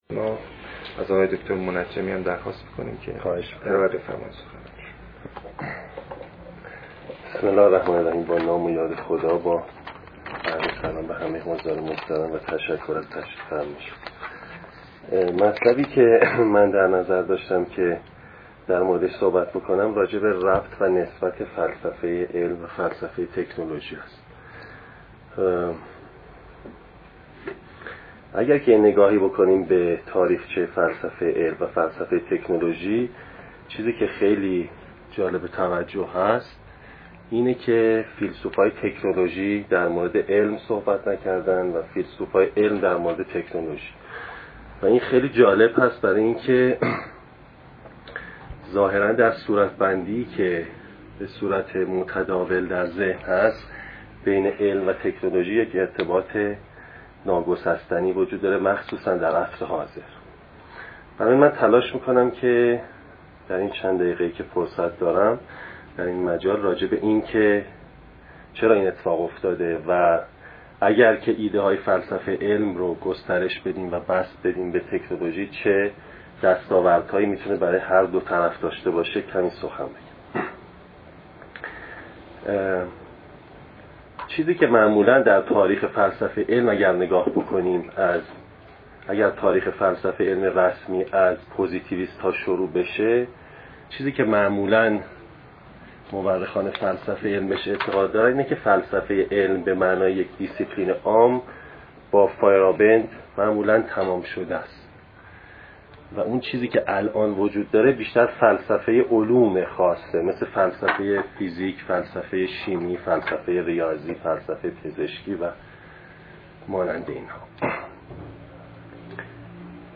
فایل حاضر سخنرانی